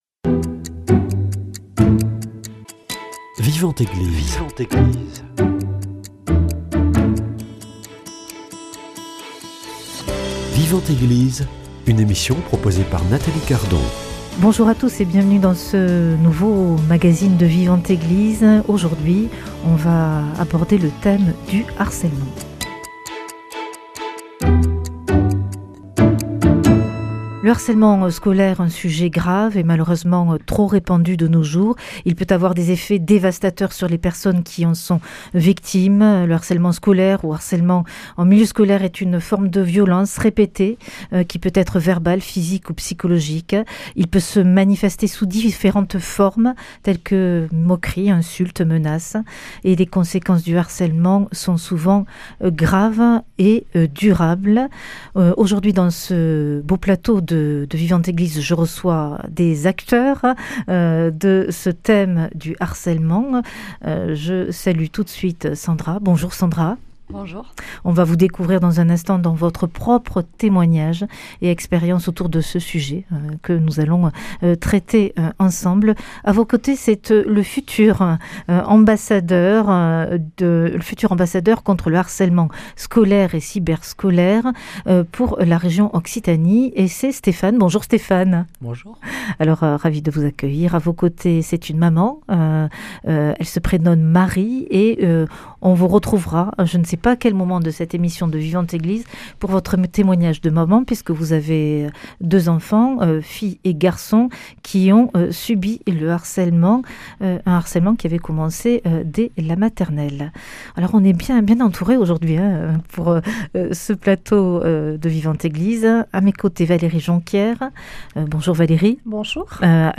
Comment repérer et aider les victimes ? En plateau